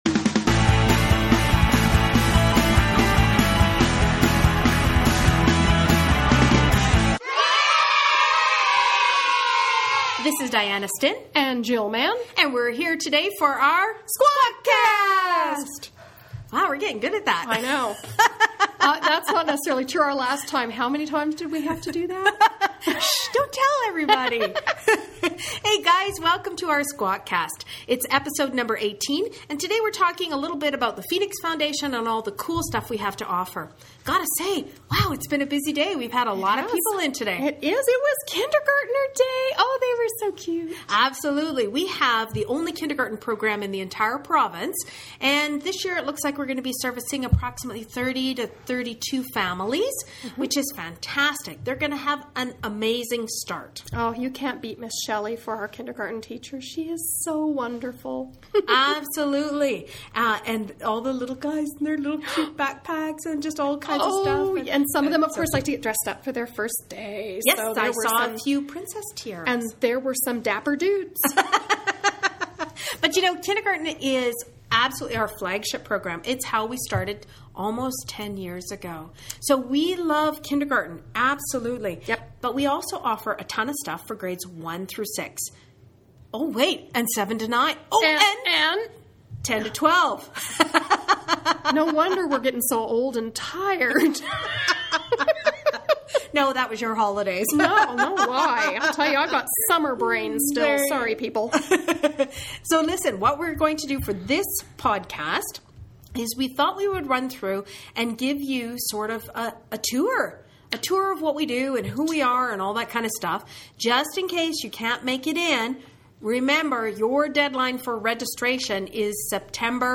WARNING: We had a great time doing this episode . . . giggles may spontaneously ensue!
Episode #18 is a virtual tour of Phoenix and what we have to offer. Right in the middle of the podcast, Global News showed up!! Thanks guys for a fun interview!